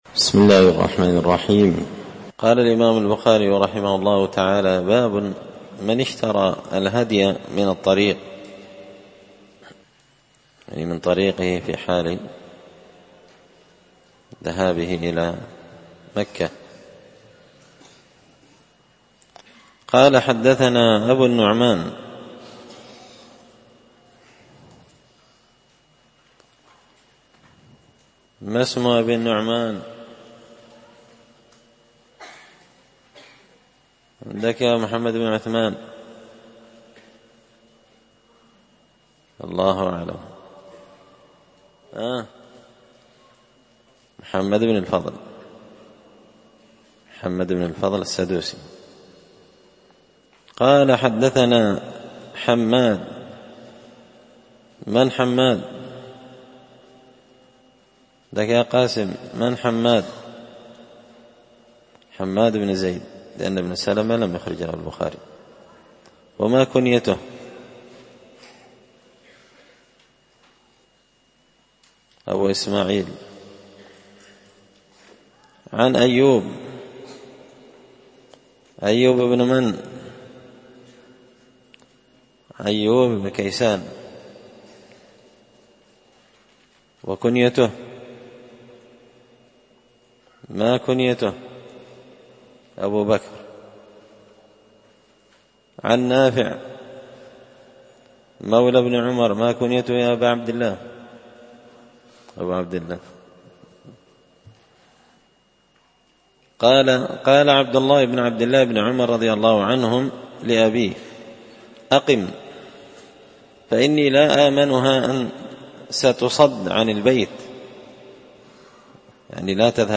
كتاب الحج من شرح صحيح البخاري – الدرس 93